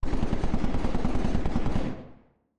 Commotion10.ogg